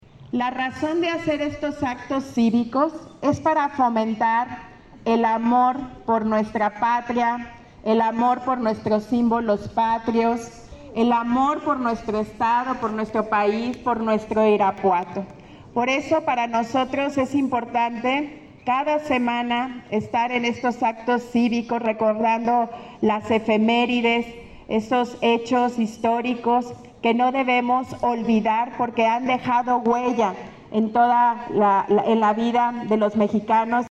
Irapuato, Gto. 21 de octubre del 2024 .- El programa anual de actos cívicos inició en el Conalep II, con la conmemoración del mes de prevención del cáncer de mama y del primer voto de las mujeres en 1953.
Lorena Alfaro Garcia, presidenta municipal